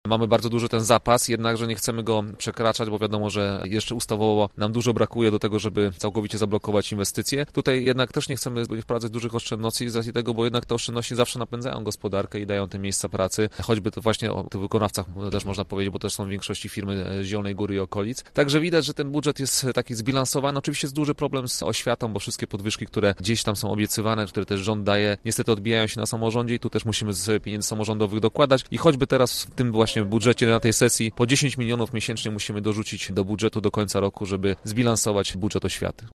Radny komentował też zadłużenie miasta, które zbliża się do 400 milionów złotych, obsługa długu wynosi 3 miliony. W skali Polski to mało, Zielona Góra jest jednym z mniej zadłużonych ośrodków w kraju – uważa Filip Gryko: